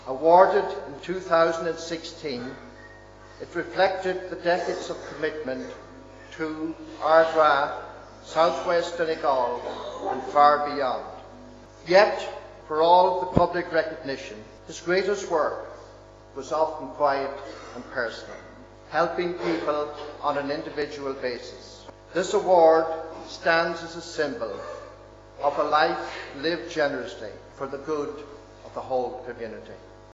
Among the gifts that was brought up during the funeral service was his Donegal Person of the Year Award: